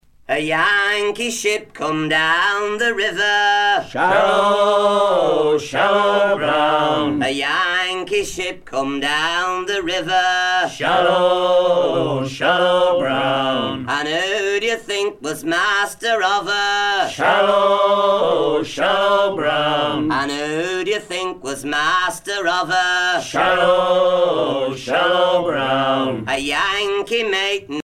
maritimes